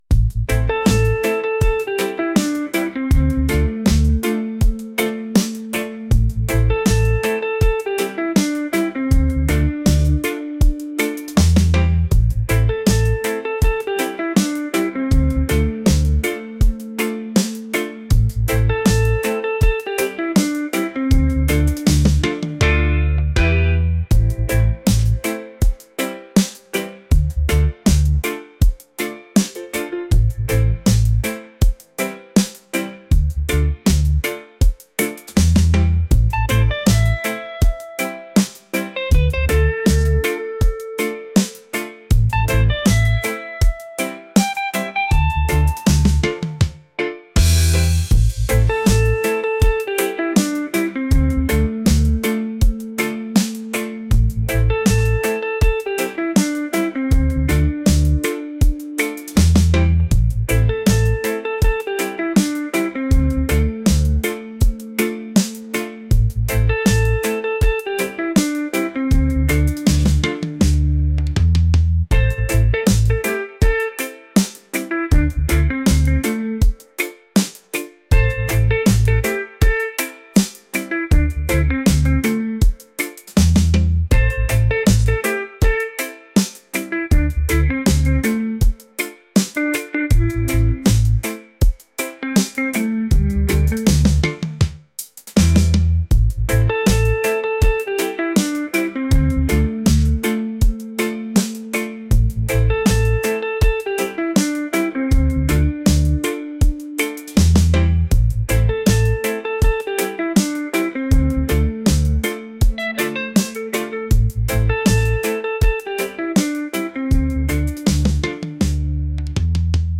mellow | reggae